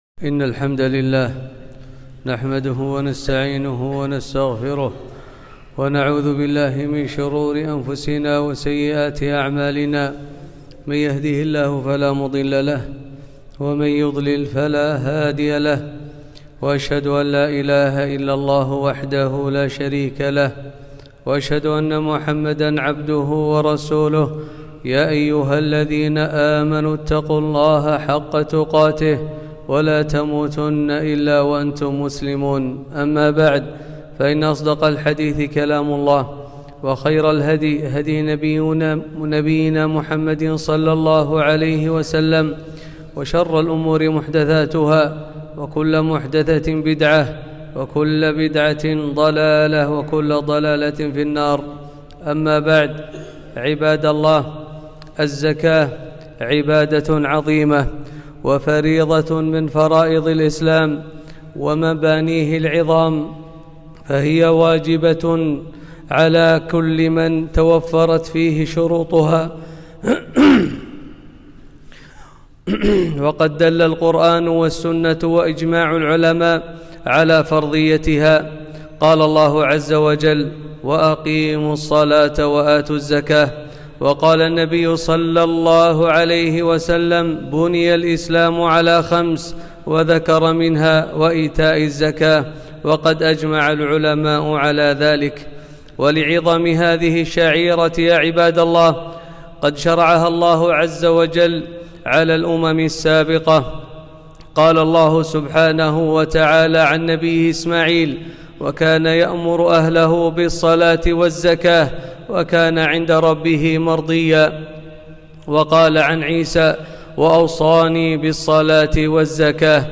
خطبة - الزكاة تزكية وطهارة